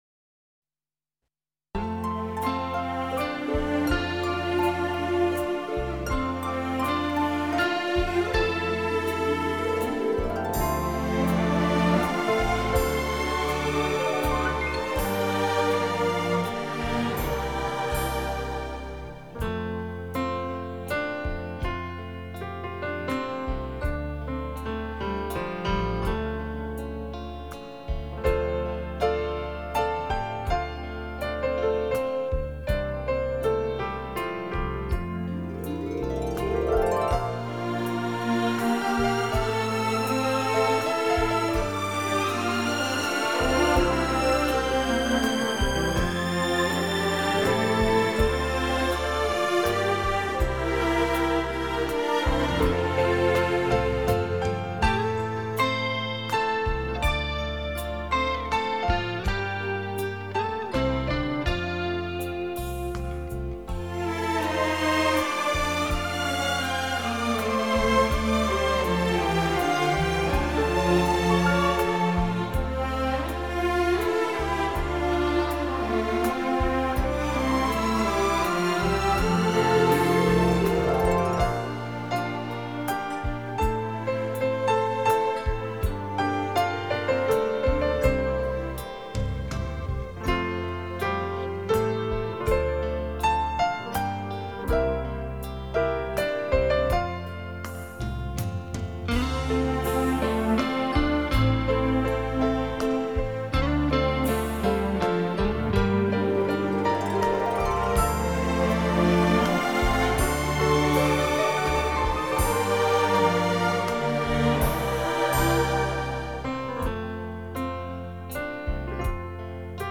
主要以钢琴，小提琴等弦乐器的协奏曲，
尝试以舒适的音响效果令人乐此不疲，希望大家喜欢。